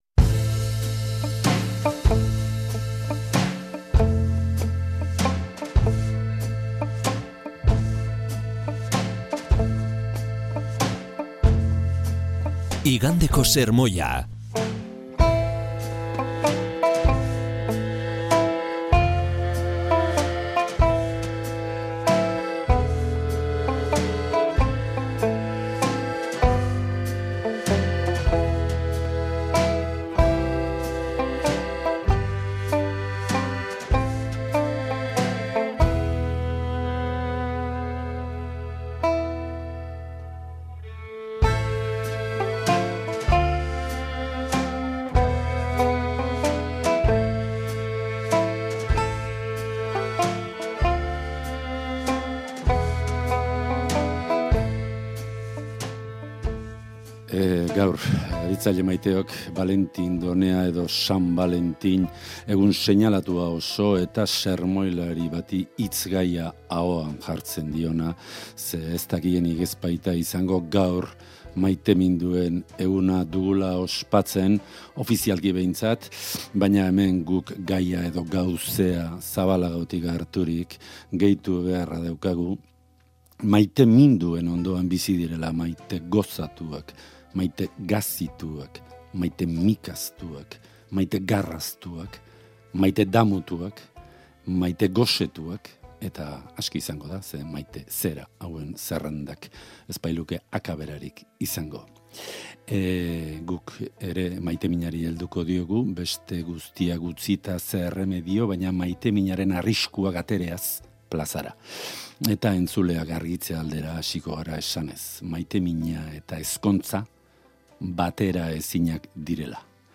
San Valentin eguneko sermoia.